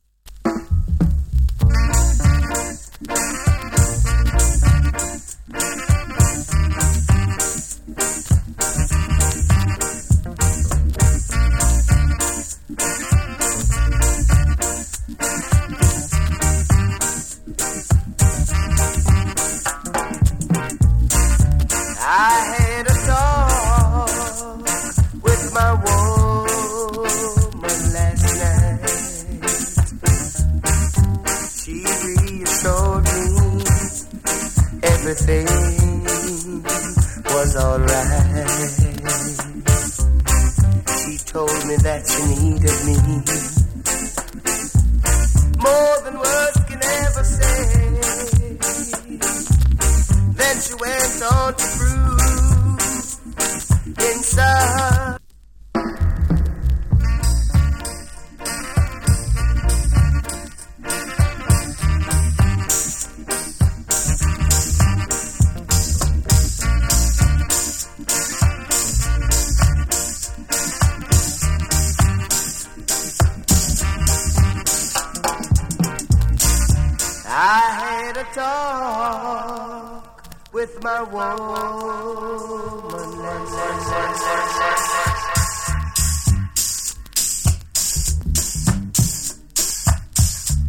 チリ、パチノイズ少々有り。B-SIDE プレスノイズ有り。